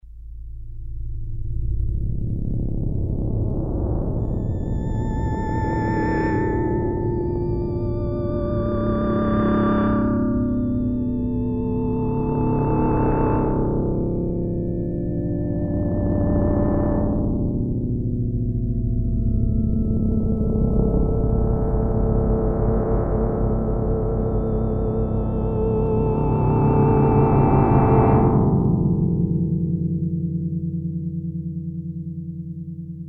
ambient DX
Synthesis: FM
ambient.mp3